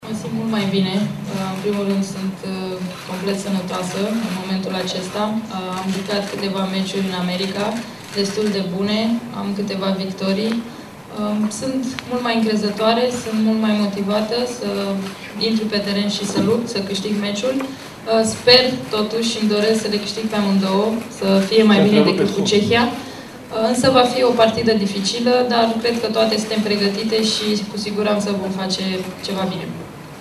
Vedeta echipei tricolore Simona Halep a spus că se simte mai bine acum comparativ cu precedenta întâlnire contra Cehiei: